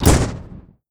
EXPLOSION_Arcade_10_mono.wav